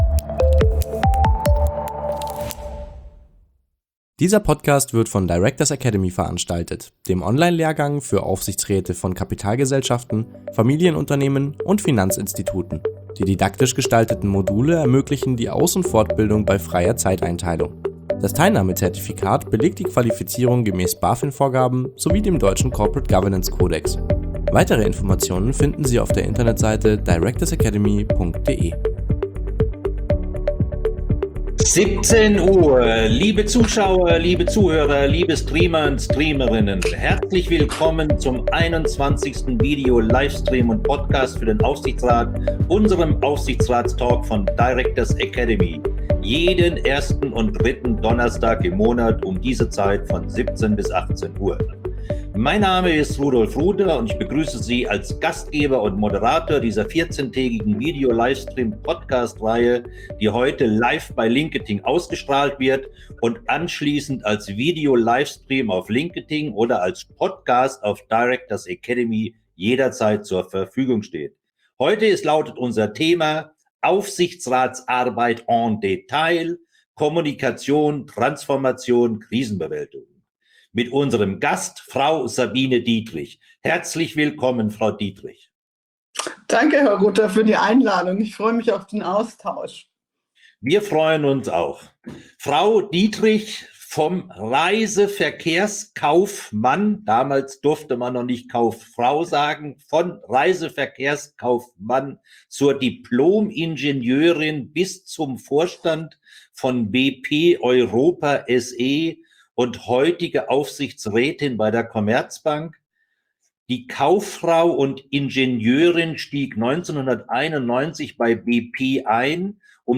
Der Podcast wird jeden 1. und 3. Donnerstag im Monat um 17:00 Uhr als Video-Livestream auf LinkedIn ausgestrahlt.